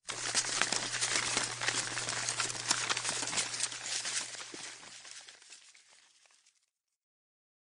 cash.mp3